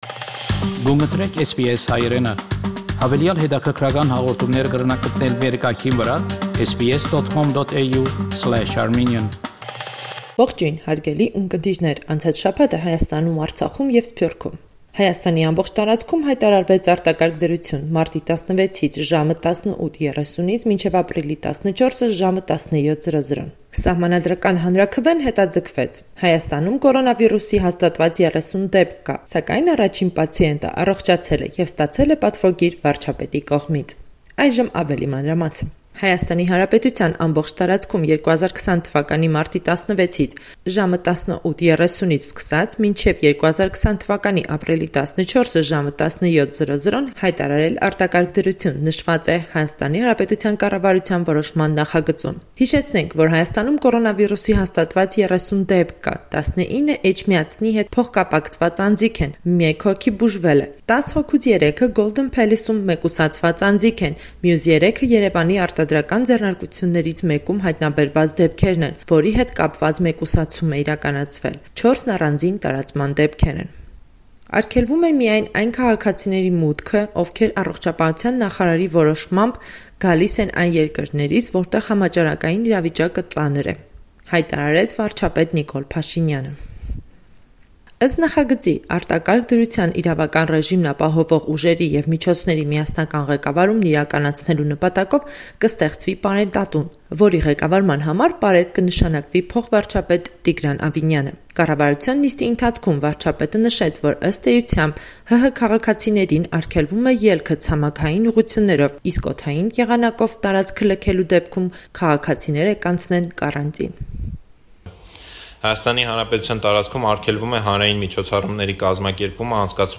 Latest News from Armenia – 17 March 2020